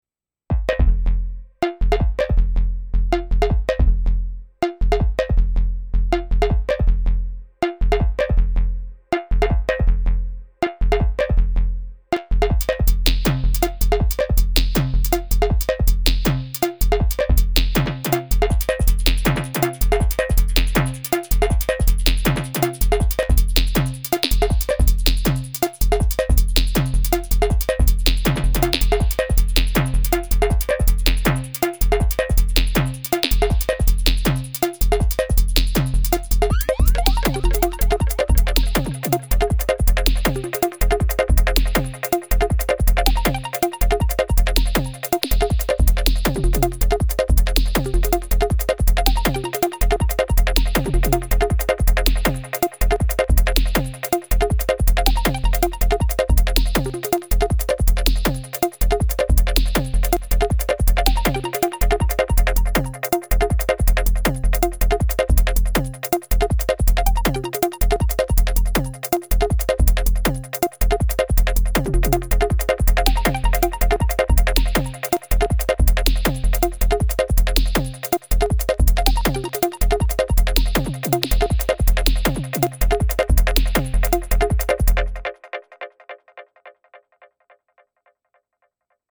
Lots of FX track filtering in these.
A blippy thing in minor @ 160 bpm